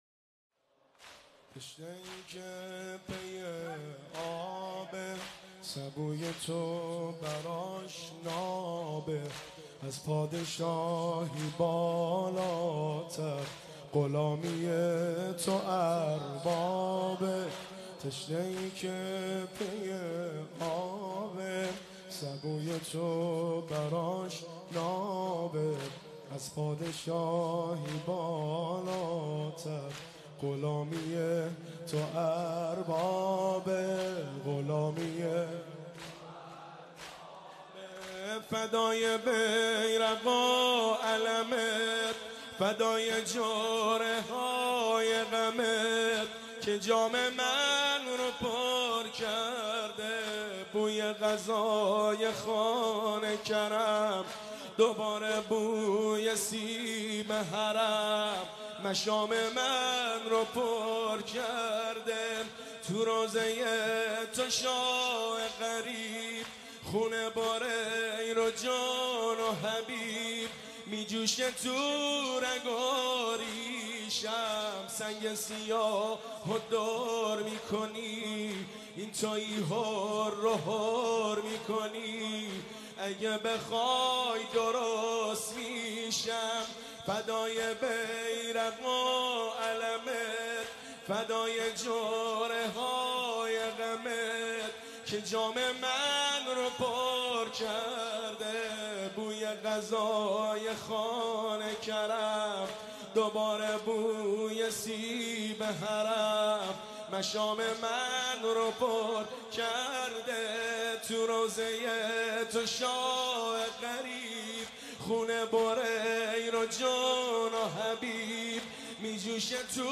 مداحی تشنه ای که پی آبه(زمینه)
شب سوم محرم 1393
هیئت خادم الرضا(ع) قم